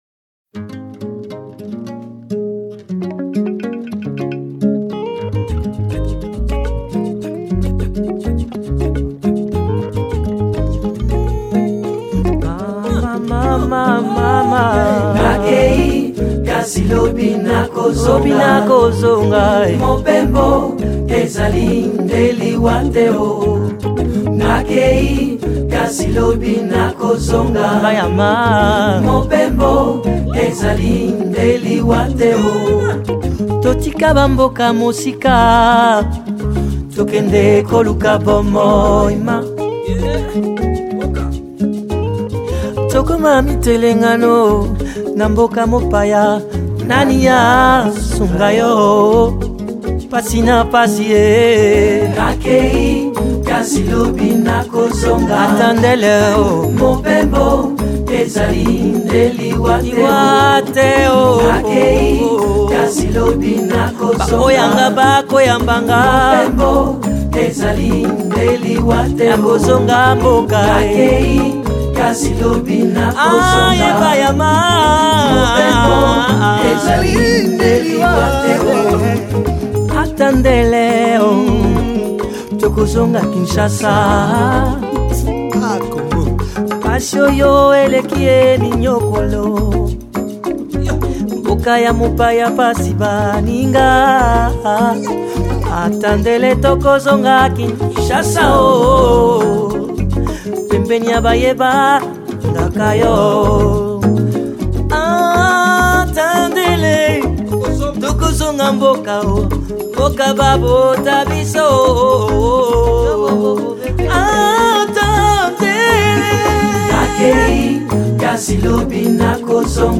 Música intimista desde o corazón de África